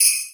drum-hitclap.wav